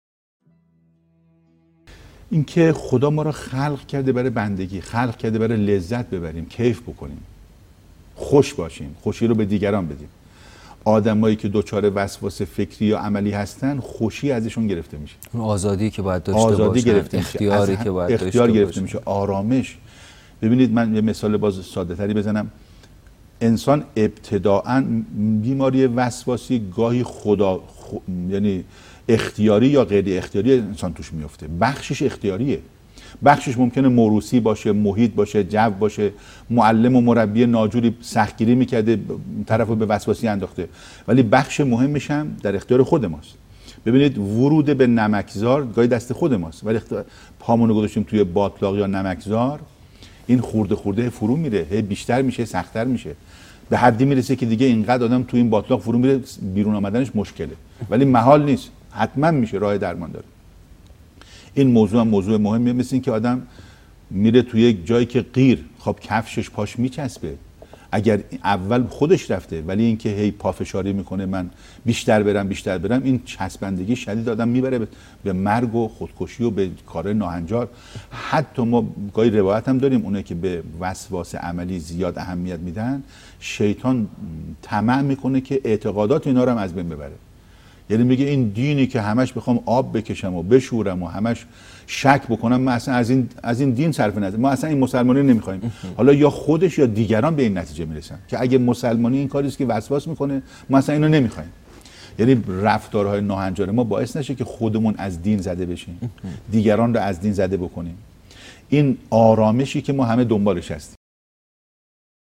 سخنرانی | آرامش حاصل از دین اسلام